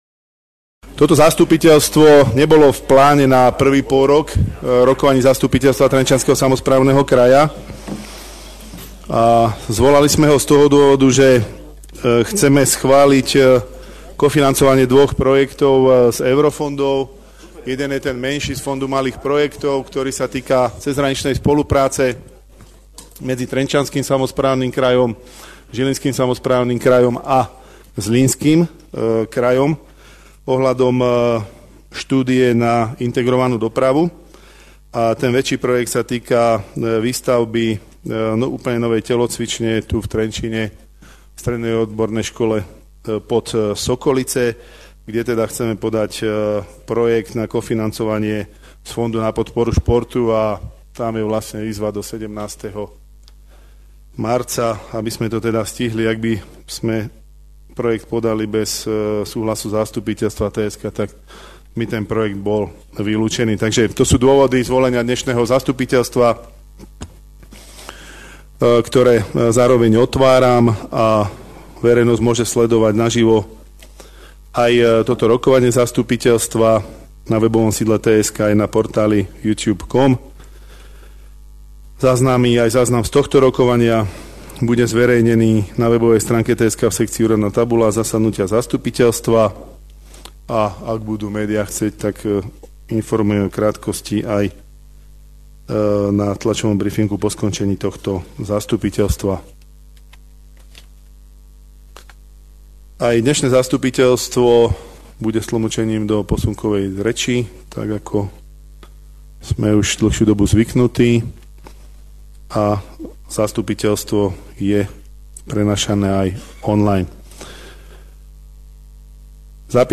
V priestoroch Kongresovej sály Úradu TSK pod vedením župana Jaroslava Bašku rokovalo 34 poslancov.